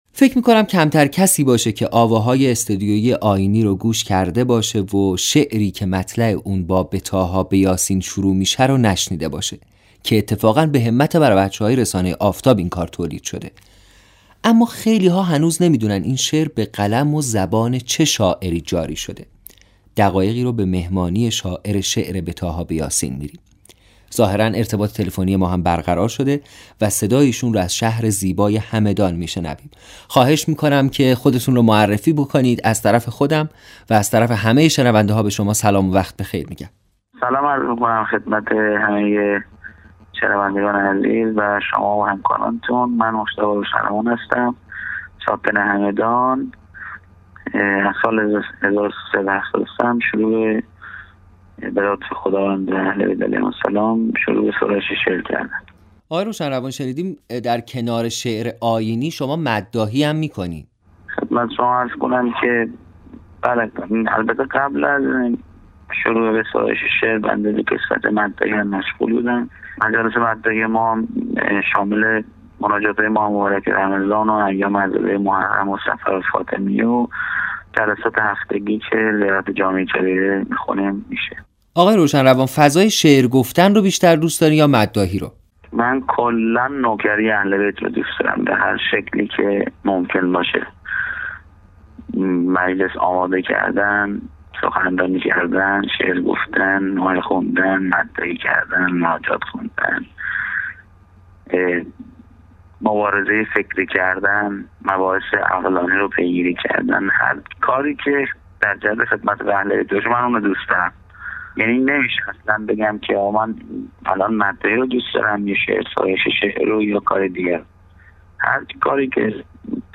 Be Taha Be Yasin_Mosahebeh.mp3
مصاحبه